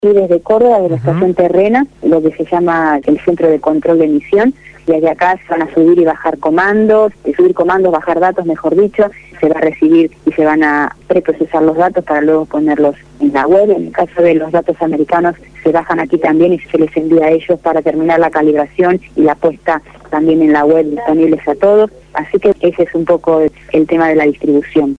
habló en Radio Gráfica FM 89.3 la mañana del viernes, antes del lanzamiento del satélite